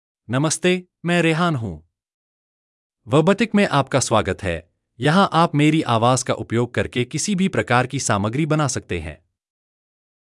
Rehaan — Male Hindi (India) AI Voice | TTS, Voice Cloning & Video | Verbatik AI
RehaanMale Hindi AI voice
Rehaan is a male AI voice for Hindi (India).
Voice sample
Listen to Rehaan's male Hindi voice.
Male
Rehaan delivers clear pronunciation with authentic India Hindi intonation, making your content sound professionally produced.